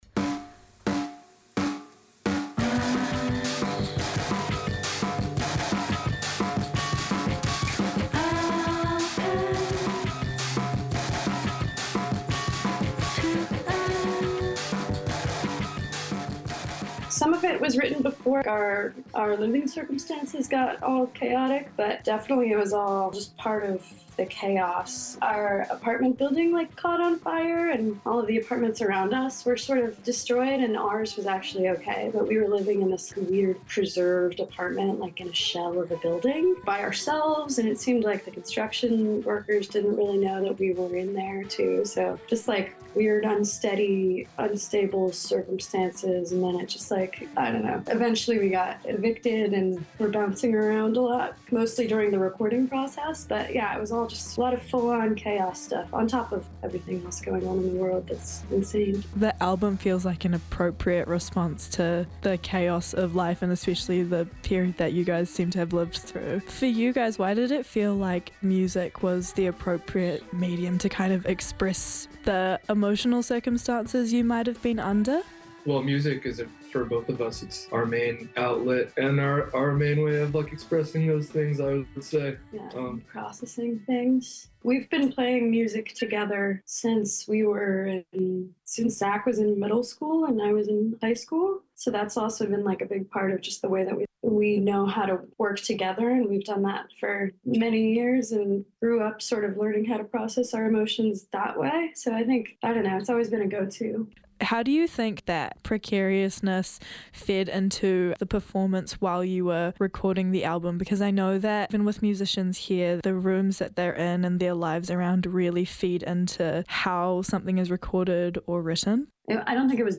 live from the Auckland studio.